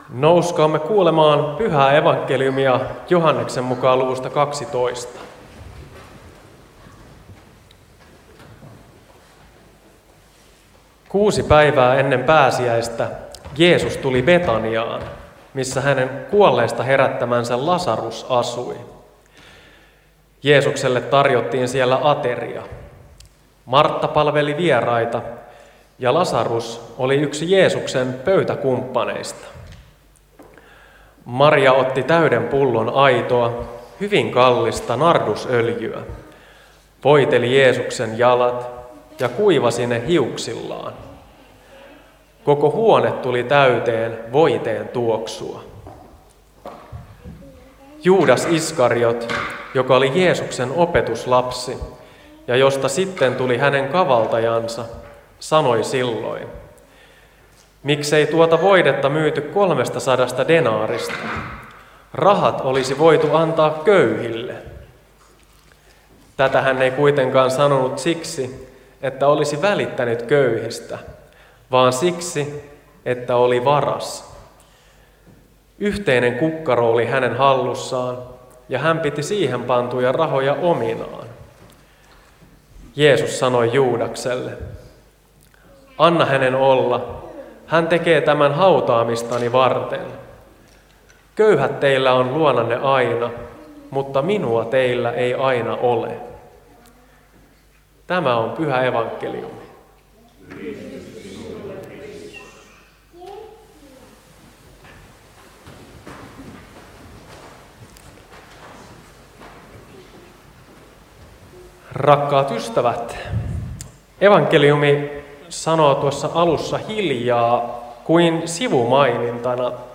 saarna Lohtajalla palmusunnuntaina